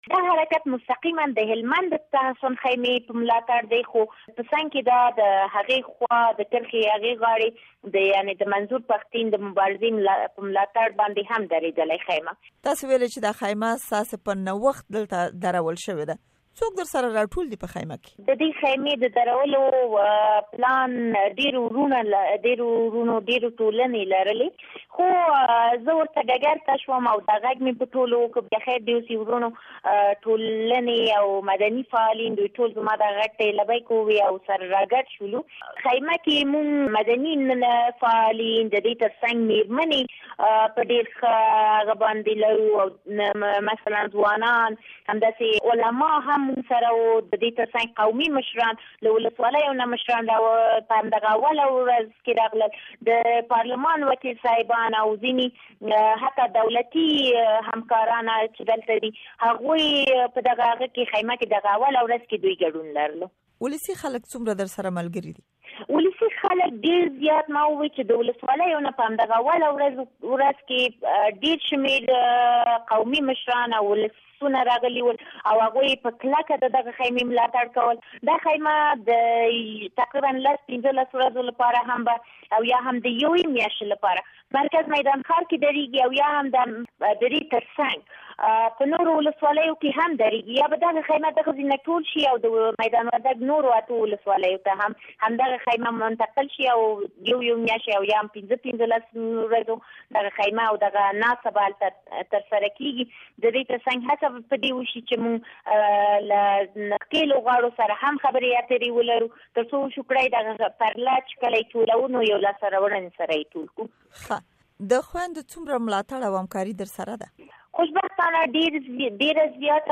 امريکا غږ سره د ظريفی غفاري مرکه